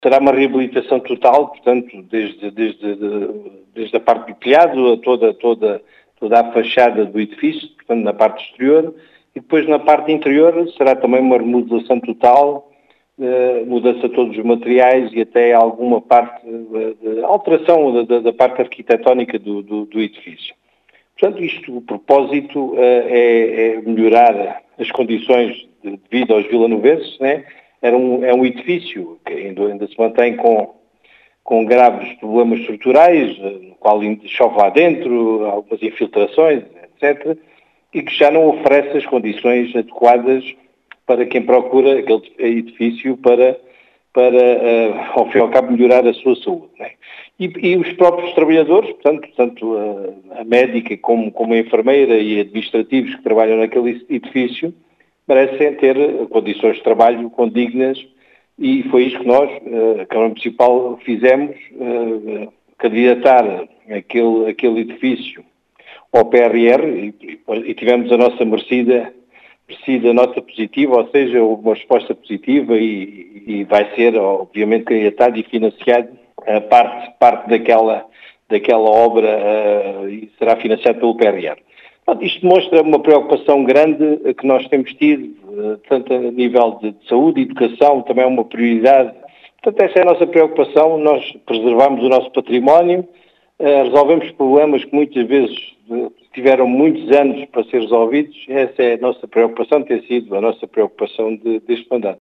As explicações são do presidente da Câmara de Alvito, José Efigénio, que fala numa “reabilitação total” do edificio.